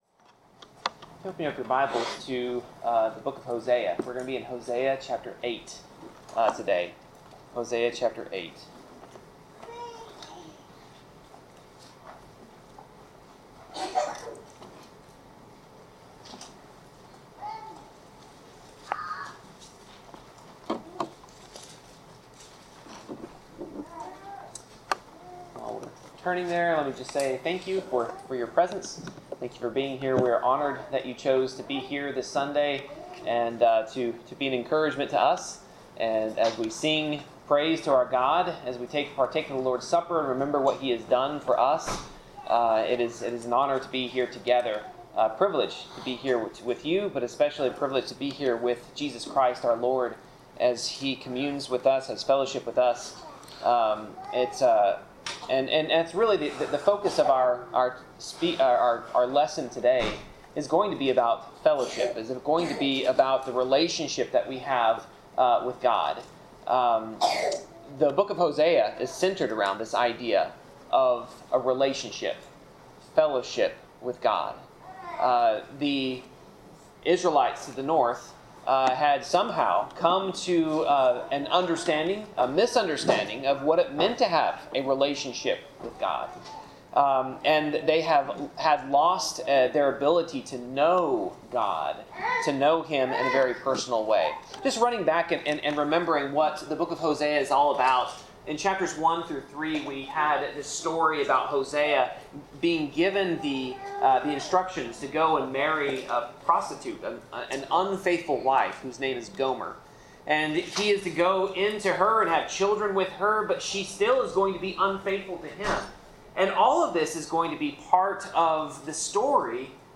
Passage: Hosea 8-10 Service Type: Sermon